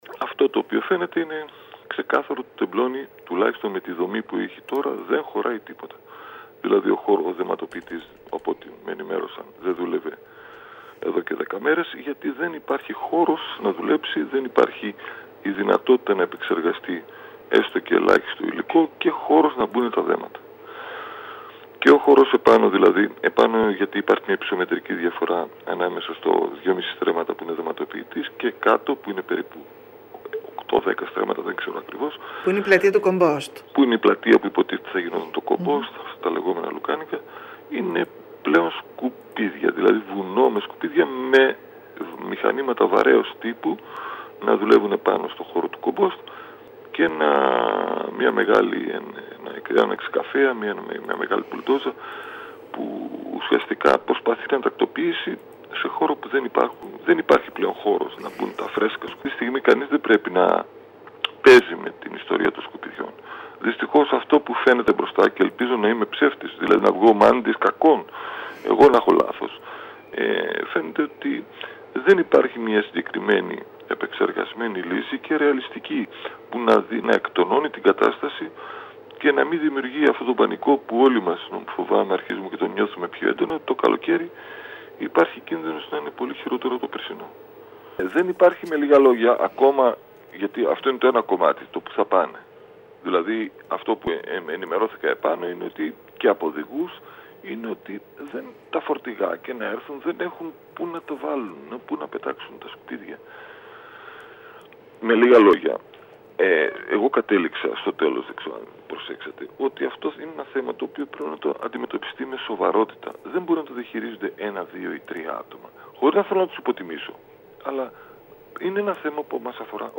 Το ΧΥΤΑ Τεμπλονίου επισκέφθηκε την περασμένη εβδομάδα ο ανεξάρτητος δημοτικός σύμβουλος Γιώργος Ραιδεστινός, ο οποίος όπως υπογράμμισε μιλώντας στην ΕΡΤ Κέρκυρας διαπίστωσε ότι ο χώρος μπροστά και δίπλα στο δεματοποιητή έχει γεμίσει  σκουπίδια με αποτέλεσμα να μην λειτουργεί εδώ και 10 μέρες ενώ η δυσοσμία είναι αφόρητη.